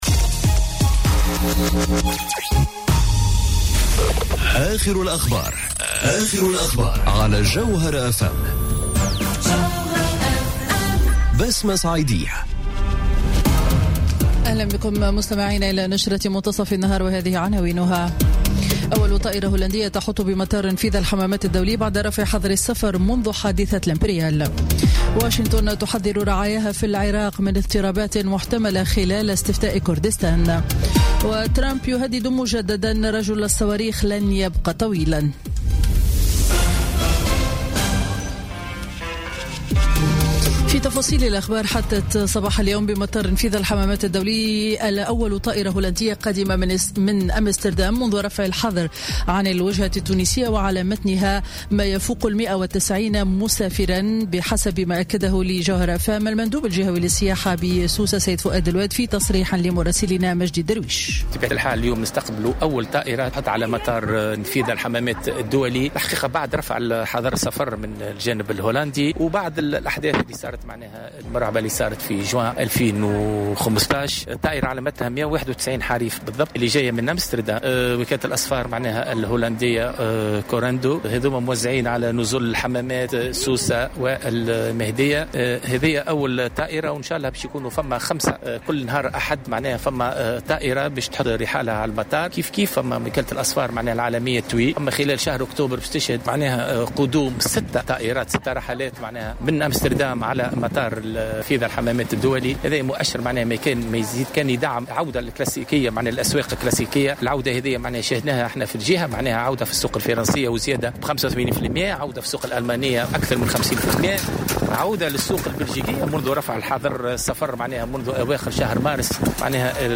نشرة أخبار منتصف النهار ليوم الأحد 24 سبتمبر 2017